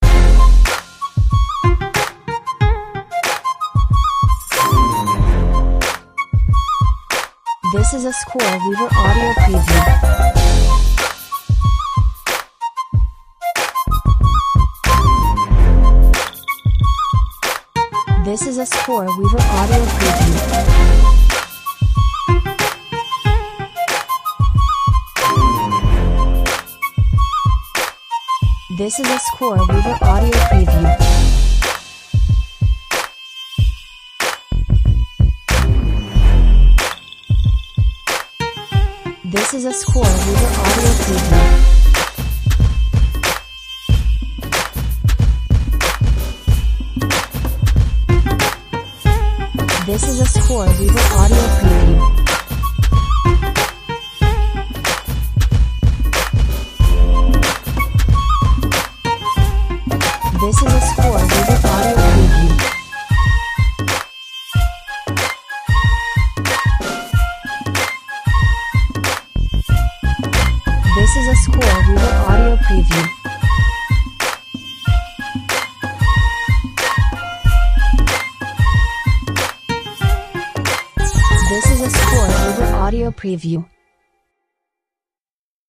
Middle eastern Hip Hop in the vein of Timbaland.